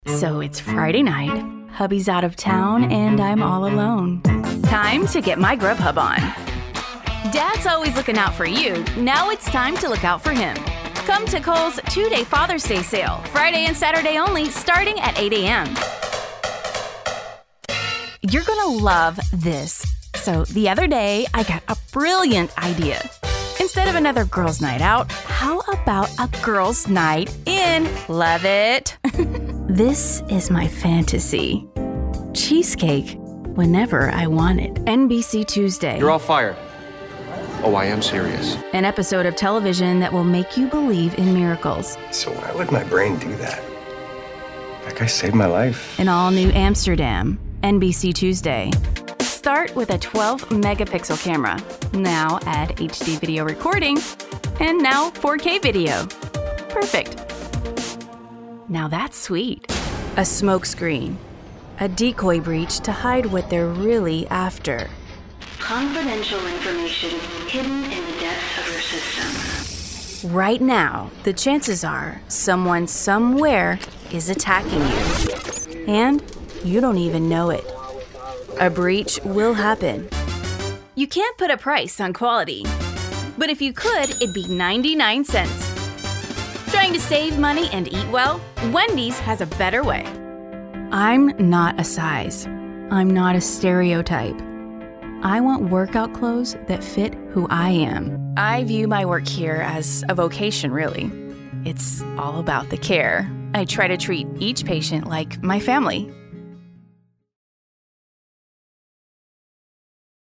Female Voice Over, Dan Wachs Talent Agency.
Upbeat, Girl Next Door, Announcer.
Commercial